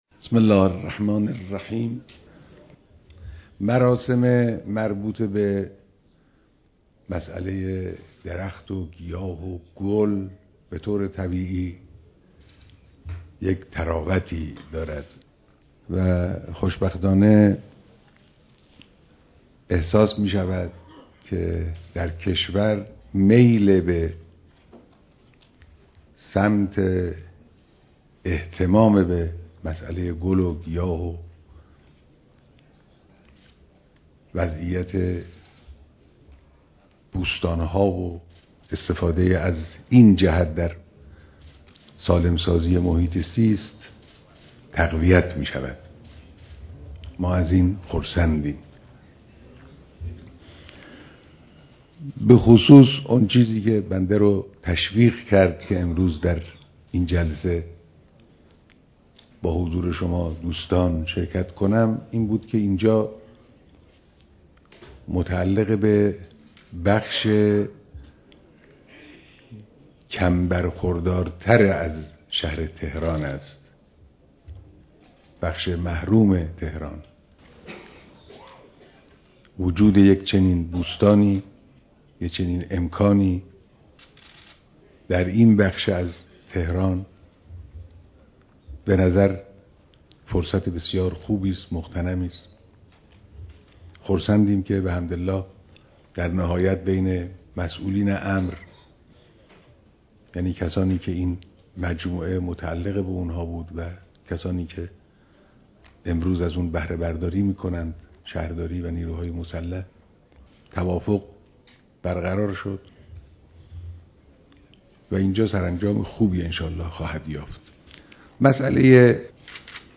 بیانات در بوستان ولایت به مناسبت روز درختكارى‌